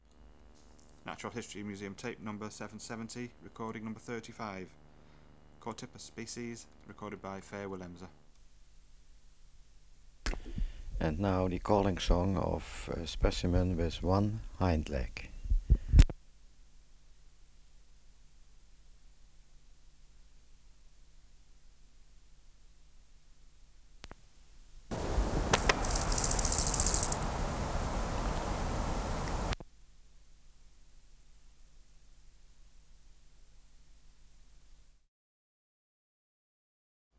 591:35 Chorthippus sp.
Natural History Museum Sound Archive